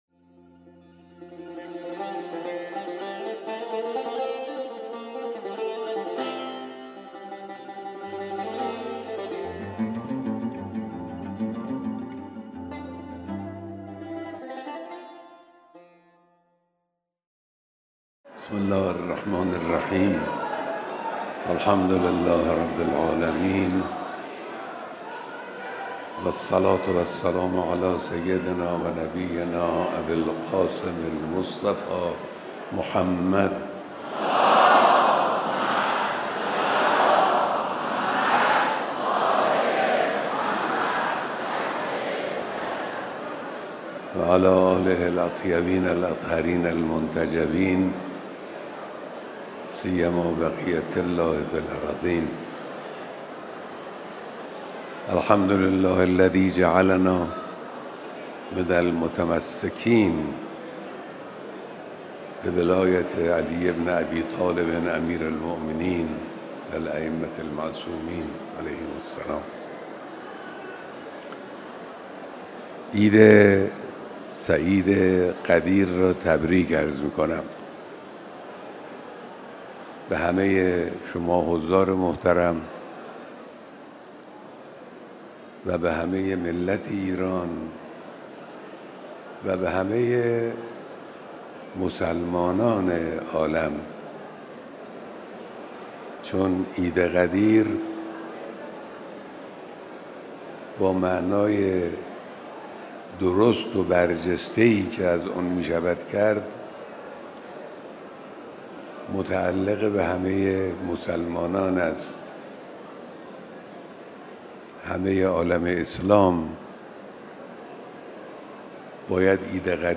بیانات در دیدار هزاران نفر از اقشار مختلف مردم در عید سعید غدیر